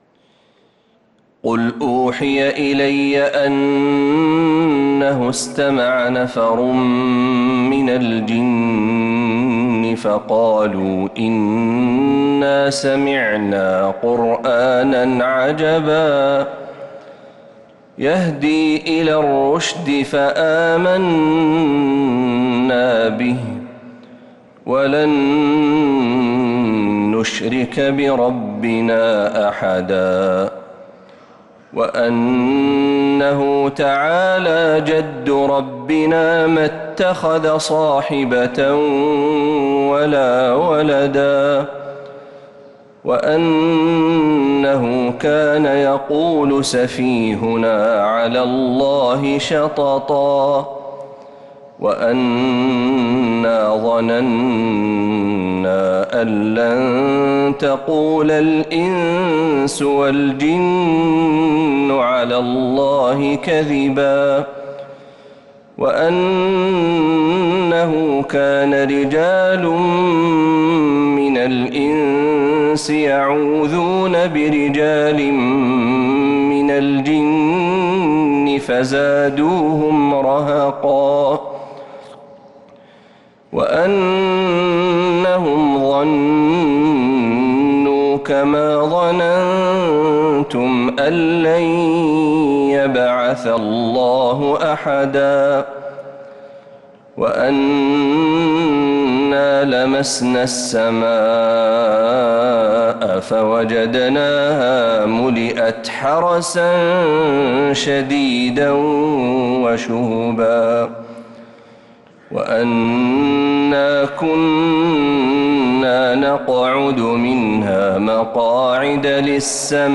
سورة الجن كاملة من فجريات الحرم النبوي